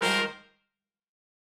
GS_HornStab-Fmin+9sus4.wav